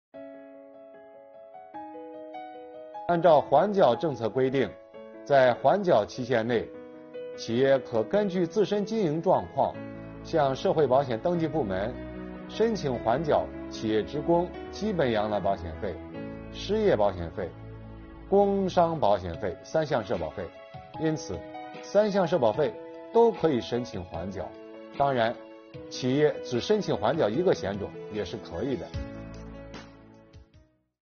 本期课程由国家税务总局社会保险费司副司长王发运担任主讲人，对公众关注的特困行业阶段性缓缴企业社保费政策问题进行讲解。